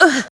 Ripine-Vox_Damage_05.wav